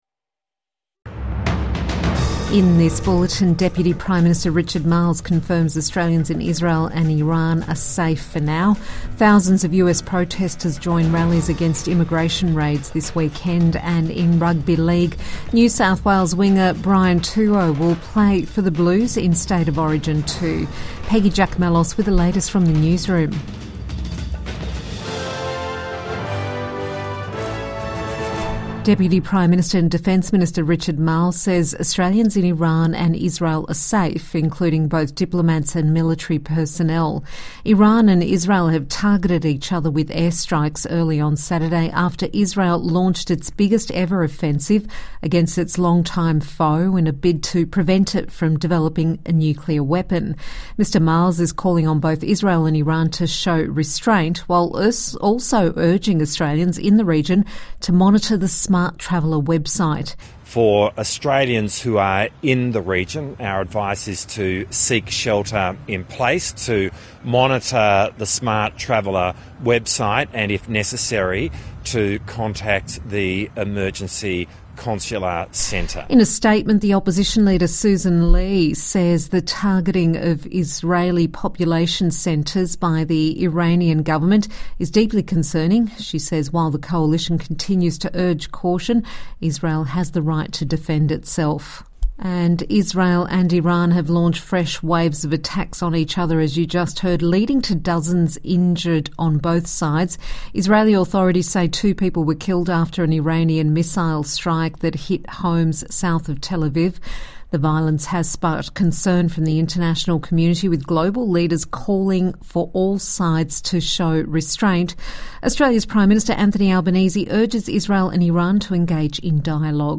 Evening News Bulletin 14 June 2025 | Government says Australians in Israel and Iran safe for now